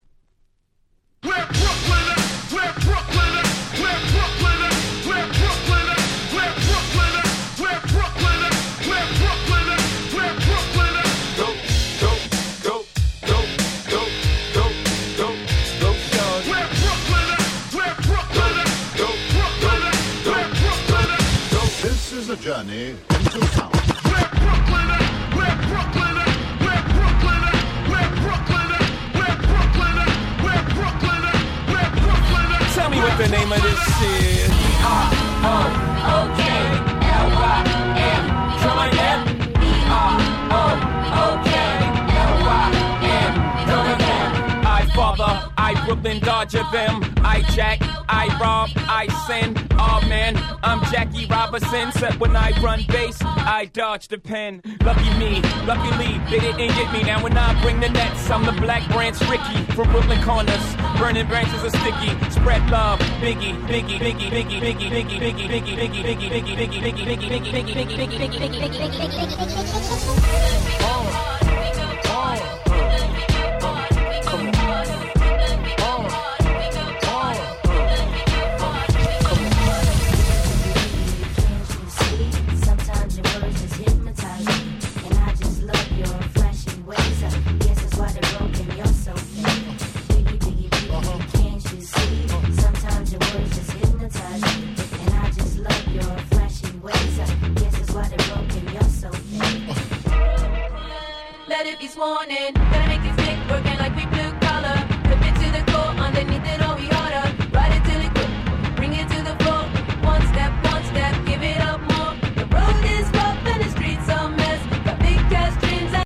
09' Smash Hit Hip Hop !!
このBoom Bap感、90'sファンにも余裕でオススメです！